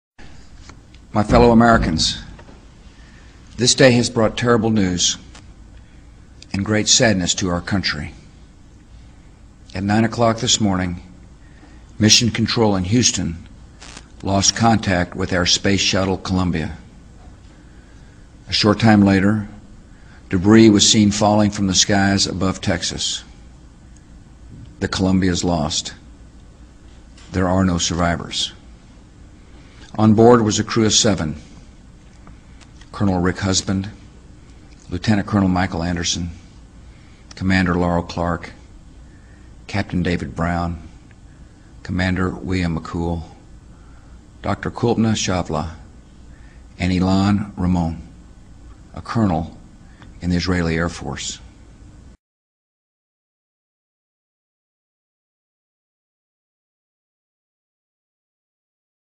The Compact Disc contains speeches that should be played through large speakers in the concert hall.